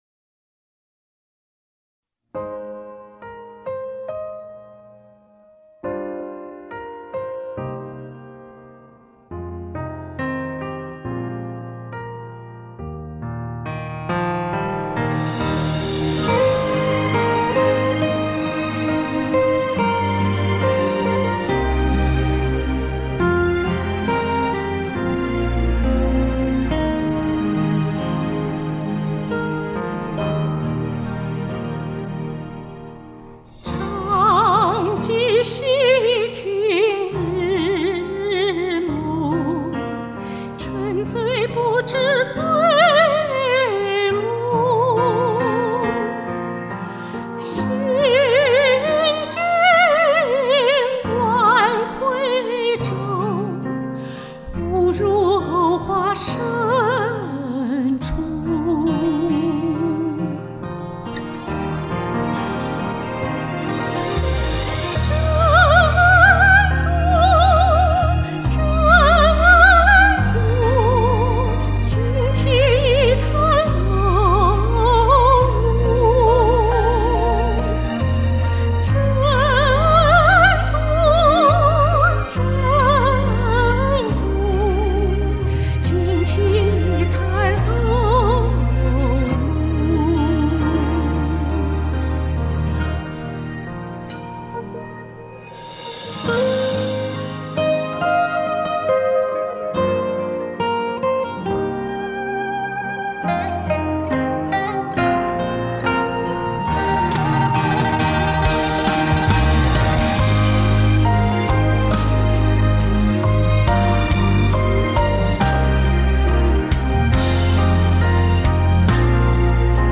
原本是一葉輕舟誤入藕花深處，歌曲的最後，感覺成了划龍舟比賽了。